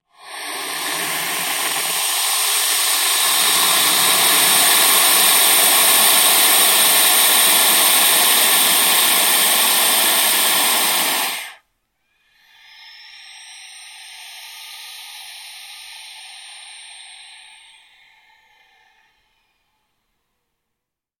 Звуки питона
На этой странице собраны реалистичные аудиозаписи: от угрожающего шипения до плавного скольжения по поверхности.